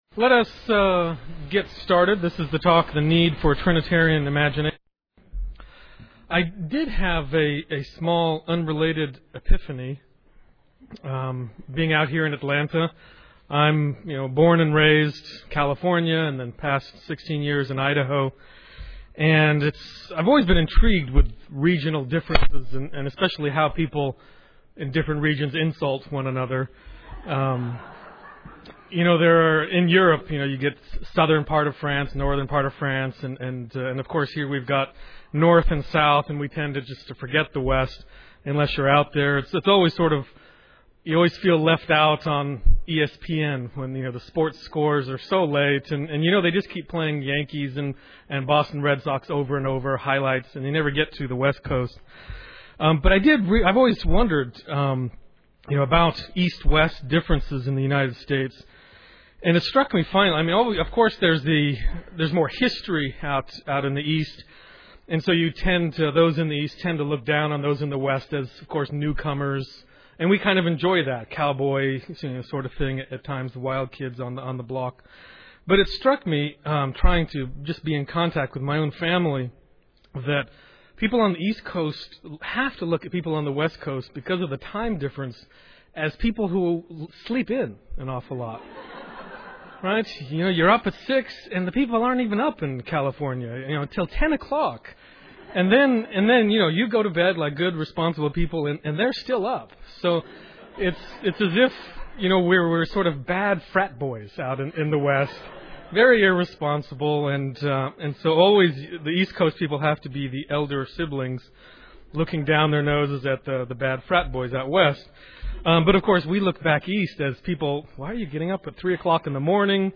2007 Workshop Talk | 0:54:20 | All Grade Levels, Art & Music
The Association of Classical & Christian Schools presents Repairing the Ruins, the ACCS annual conference, copyright ACCS.